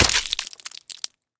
claw3.wav